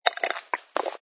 sfx_slurp_glass04.wav